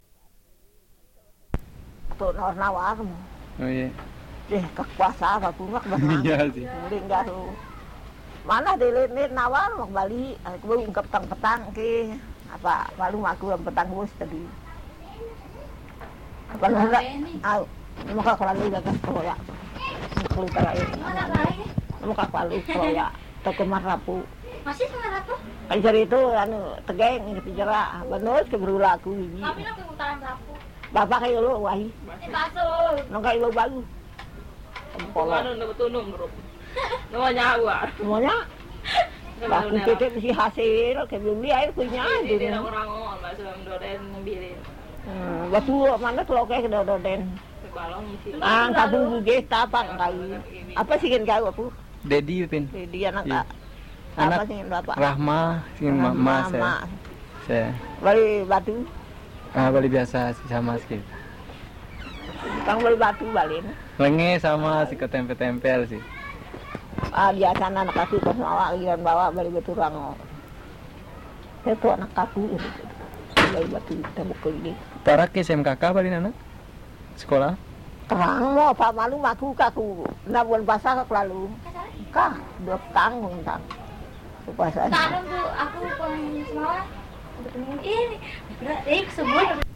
conversation
a lady
199x_mm_conversation01.mp3